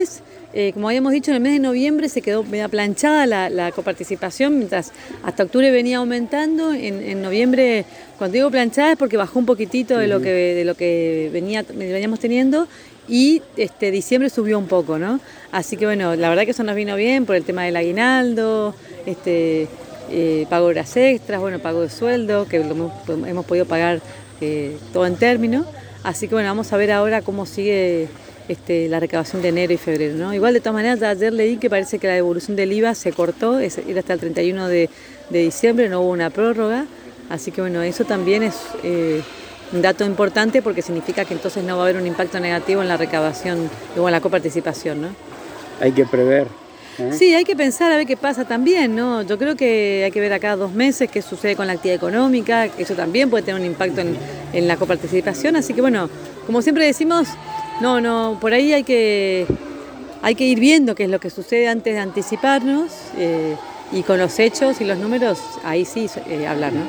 La Intendente de Apóstoles María Eugenia Safrán en diálogo exclusivo con la ANG manifestó que se continúan los trabajos de arreglos y entoscados de los caminos vecinales y calles de la ciudad. Además de continuar trabajando para solucionar los inconvenientes que causó la tormenta de viento que azotó Apóstoles la semana pasada.